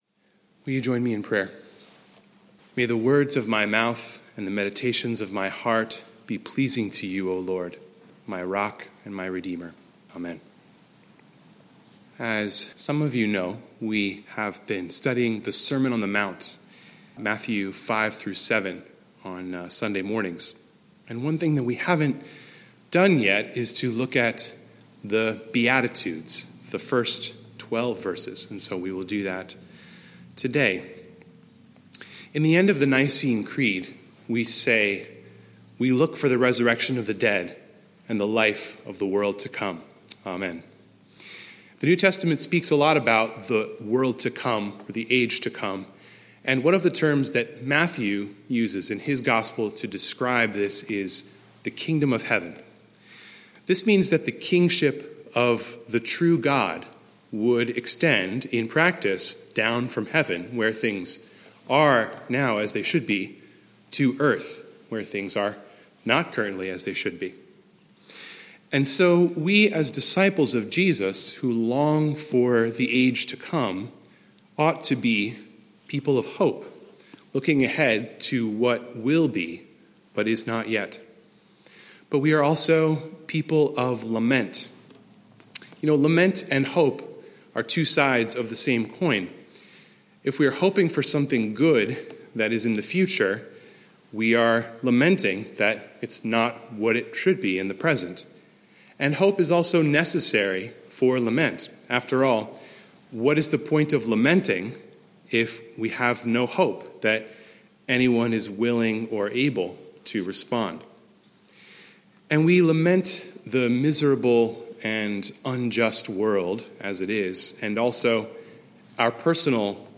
Meditation Text: Matthew 5:2–12